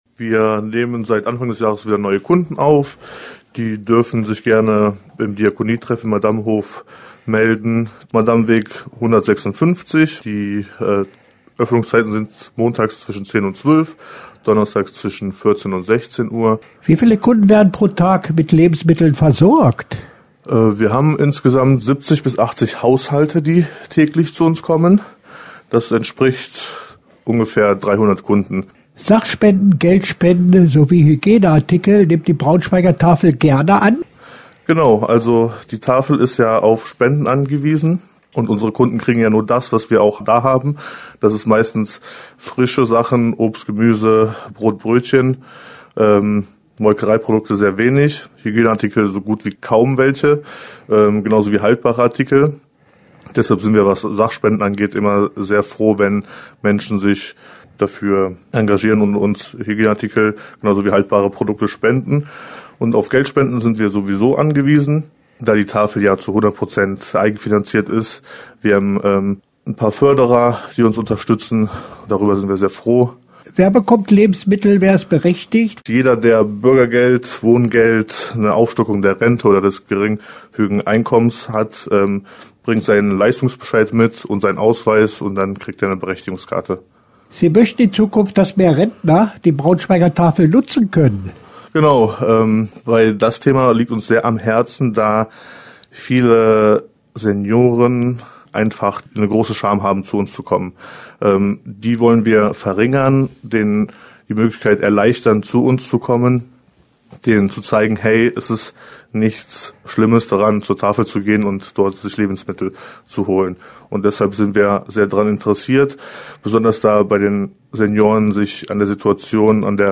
Interview-Tafel-BS.mp3